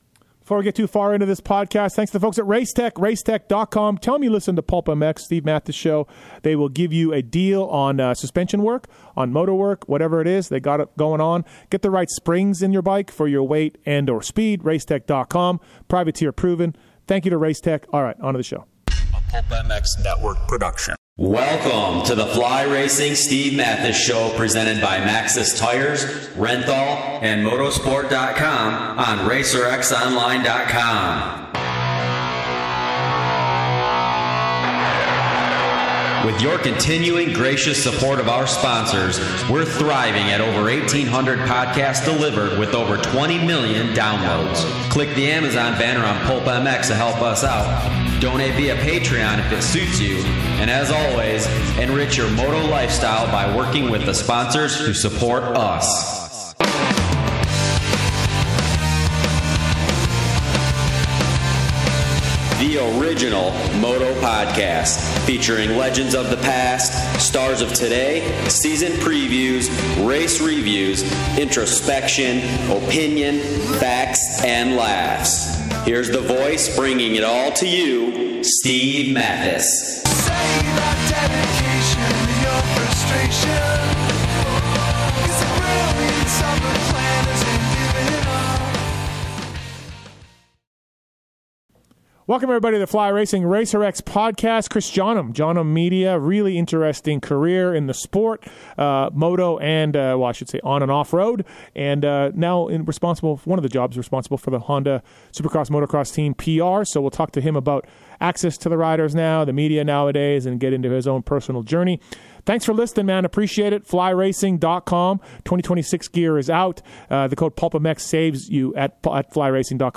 Podcast Interview